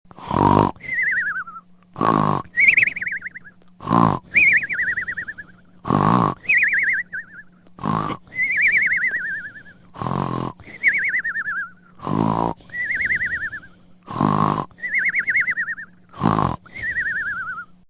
Cartoon Snoring With Whistle Sound Effect Free Download
Cartoon Snoring With Whistle